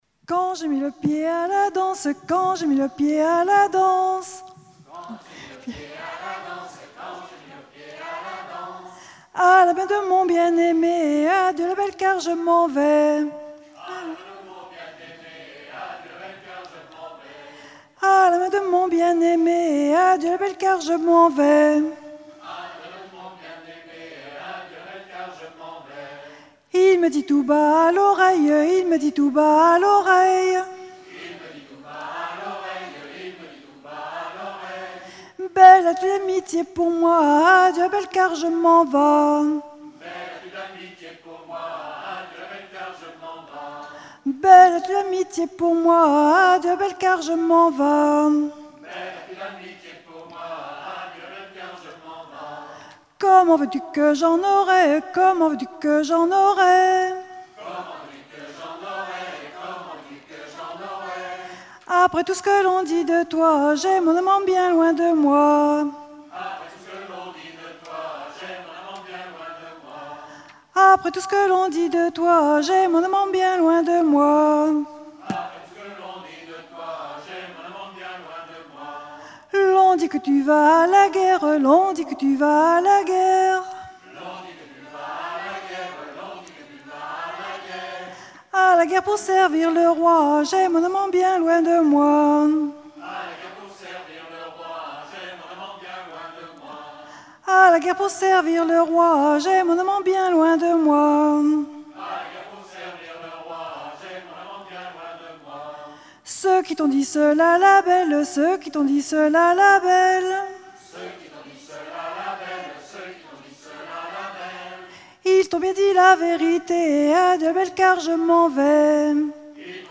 ronde à la mode de l'Epine
Festival de la chanson traditionnelle - chanteurs des cantons de Vendée
Pièce musicale inédite